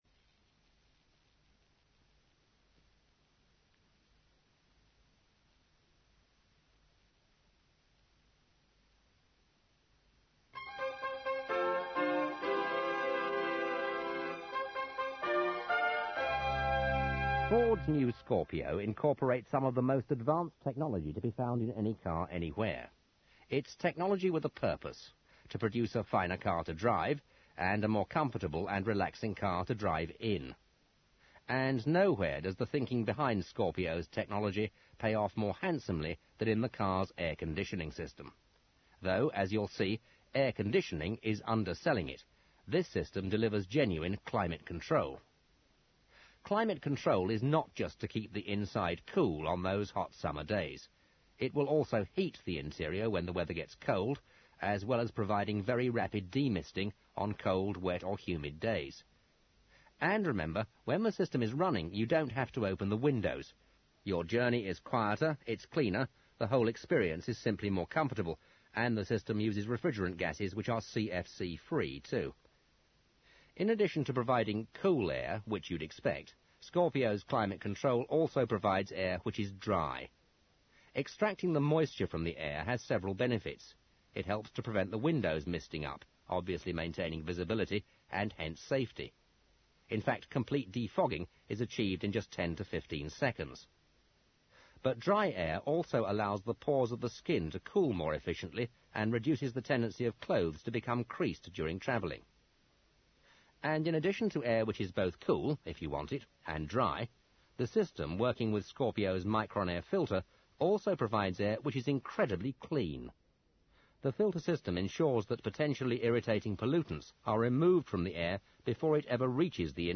To help the new driver become acclimatised (excuse the pun) to the system they even provided an Audio Cassette with the vehicle.